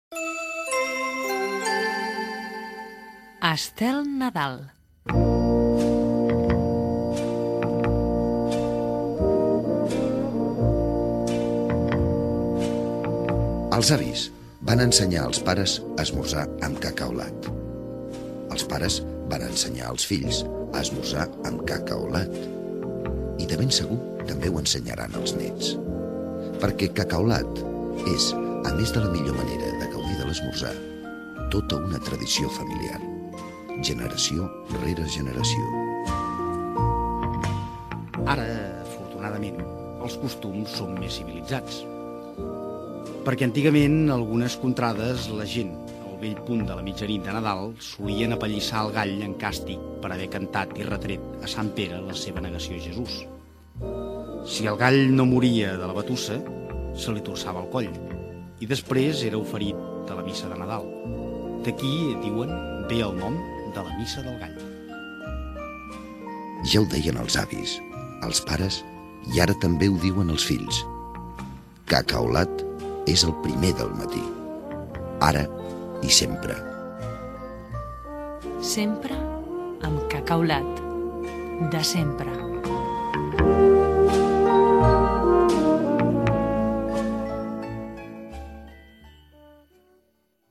Indicatiu del programa, espai publicitari de Cacaolat. Els maltractaments als galls durant el temps de Nadal
FM